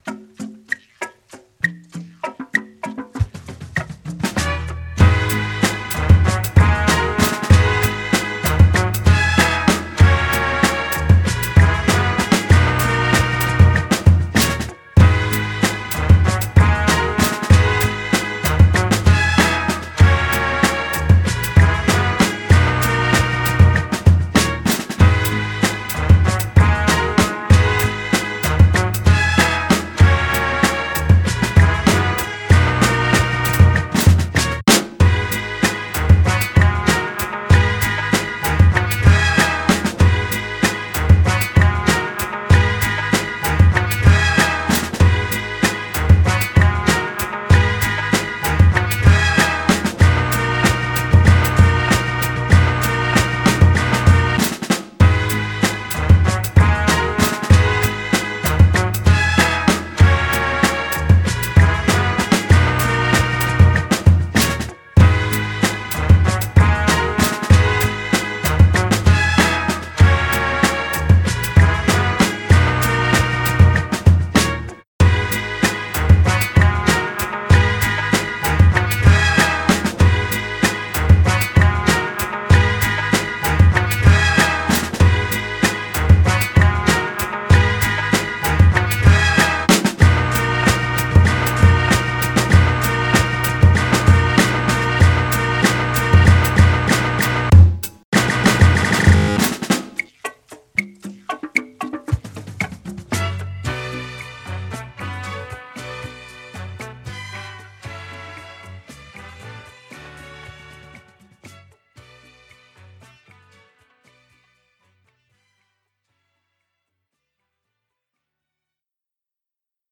Home > Music > Pop > Smooth > Medium > Happy